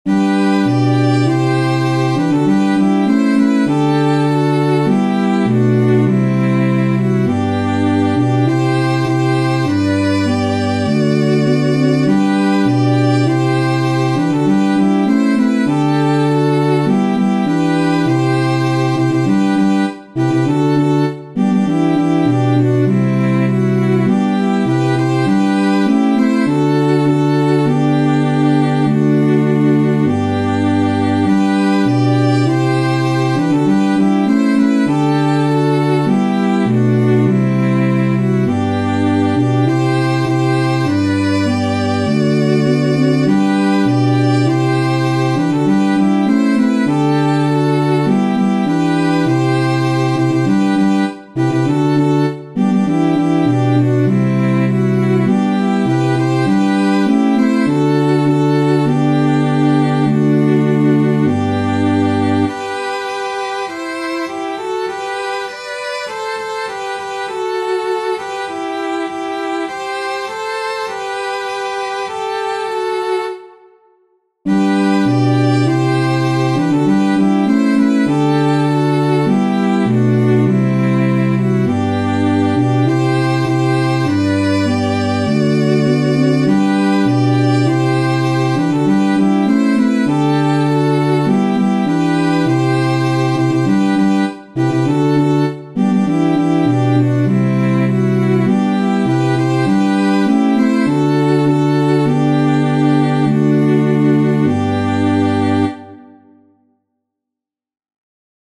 • Catégorie : Chants d’Acclamations.